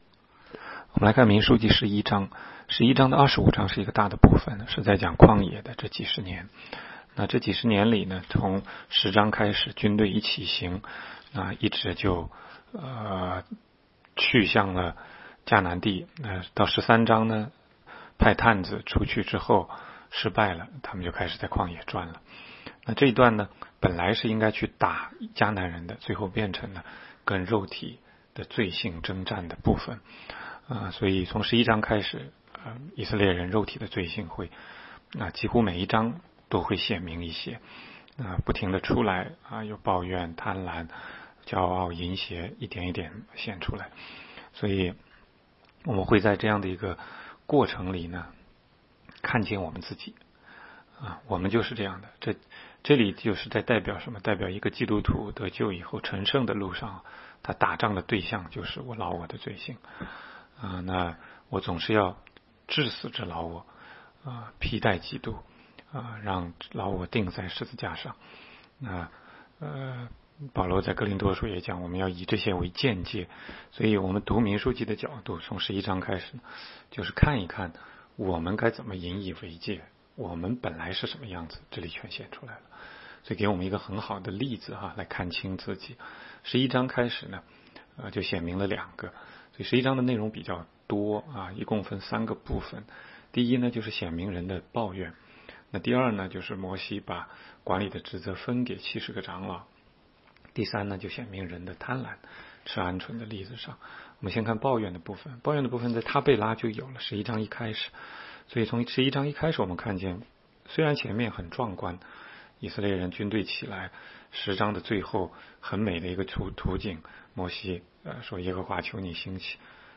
16街讲道录音 - 每日读经-《民数记》11章